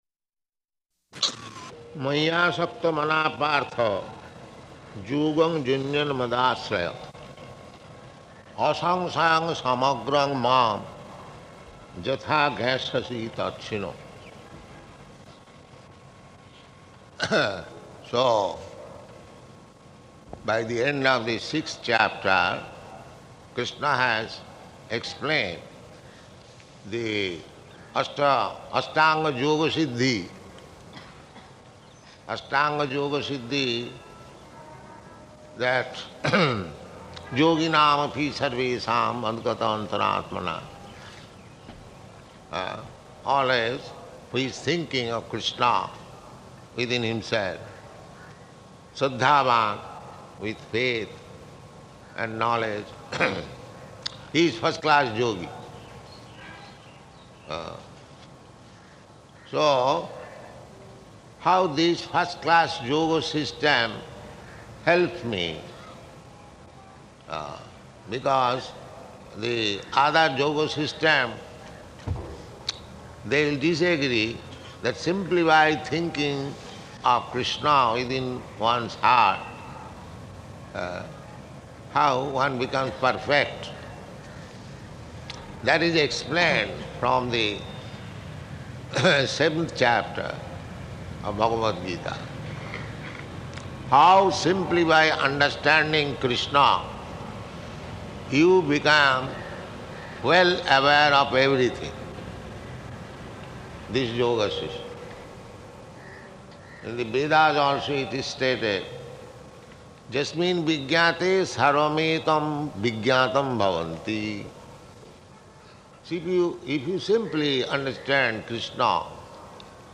Type: Bhagavad-gita
Location: Ahmedabad